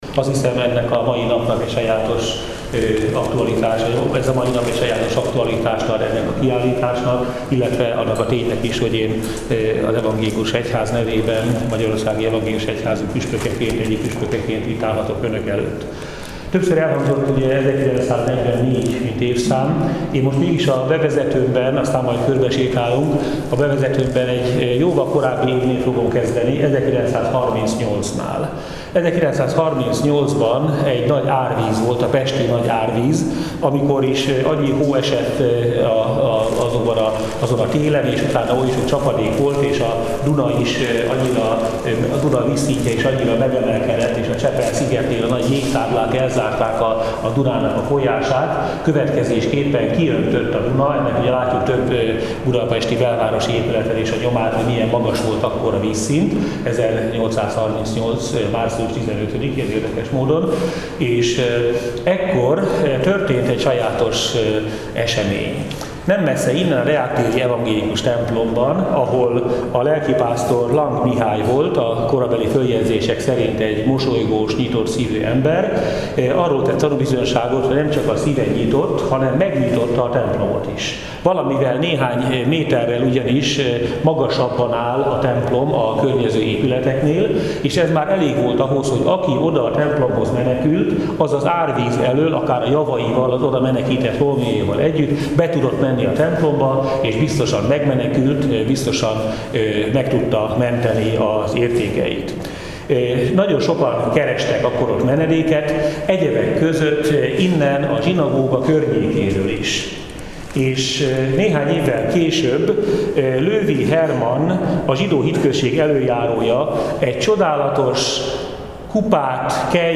Ezekkel a bevezető gondolatokkal kezdte meg Fabiny Tamás evangélikus püspök rendhagyó tárlatvezetését.
Fabiny Tamas Zsido Muzeum01.mp3